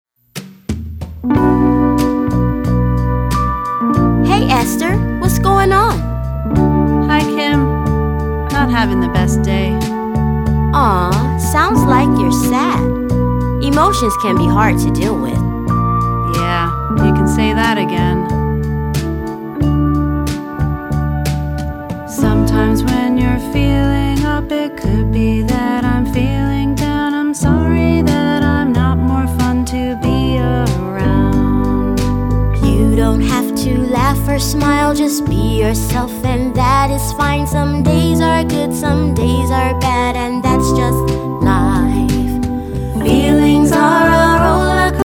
rock songs